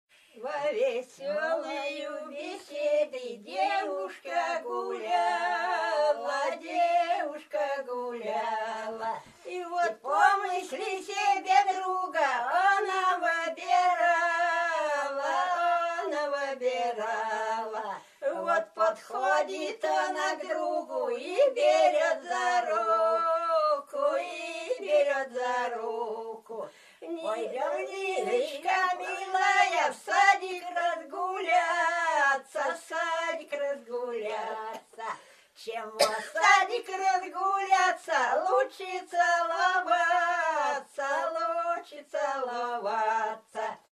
«Святошная» песня
из с.Крюковка Лукояновского р-на Нижегородской обл.